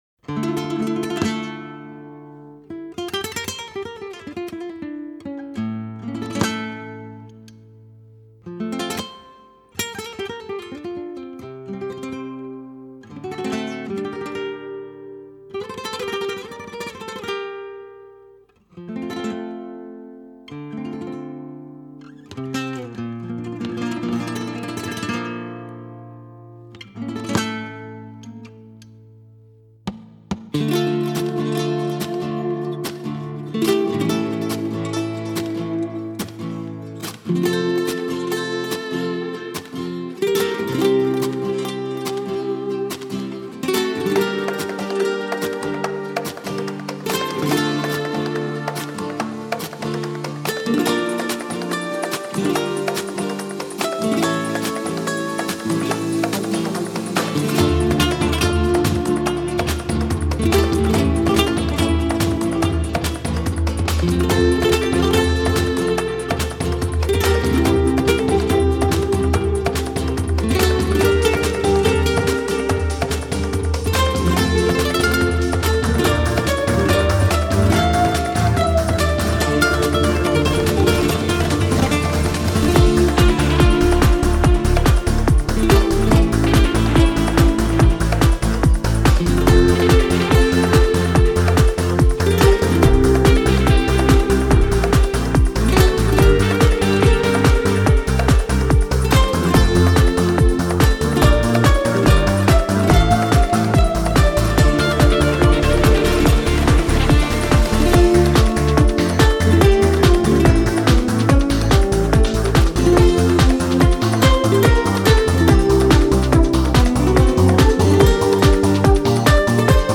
J'avais pensé à la répétition mais il y a juste des instruments en plus et le rythme ne change pas assez.